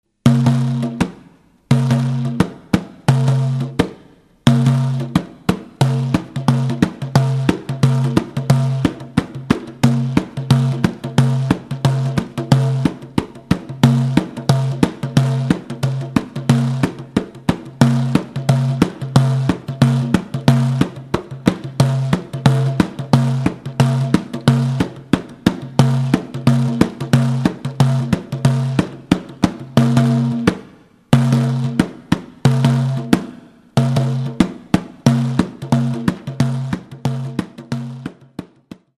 Ecoute >> Le bendir (ou bendhir ou abendair ou abendaïr) :
Instrument à percussion (apparenté au riqq, tambourin arabe égyptien)
Le bendir est à mi-chemin entre le tambourin et la caisse claire.
Certains bendir possèdent, tendus sur la peau, trois fils perlés pour augmenter la résonance de la peau et lui donner un timbre caractéristique voisin de la caisse claire de batterie.
bendir.mp3